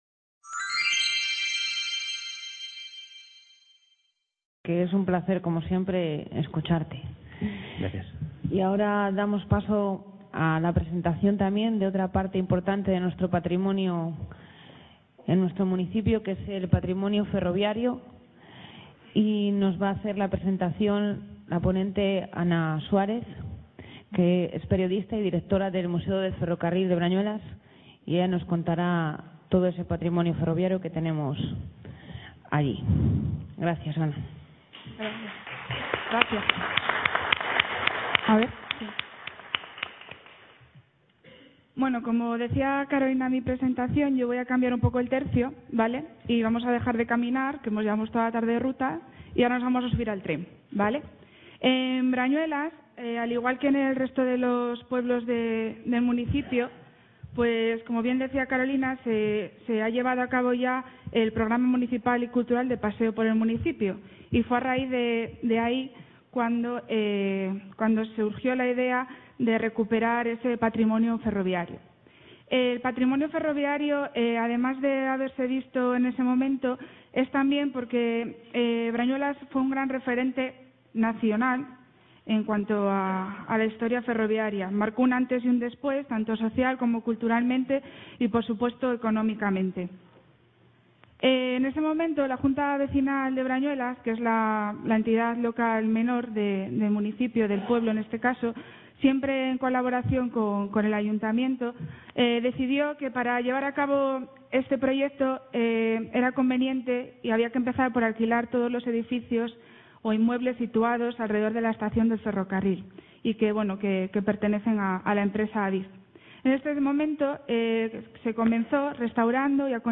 CA Ponferrada - Jornadas sobre Patrimonio Industrial y Minero del Bierzo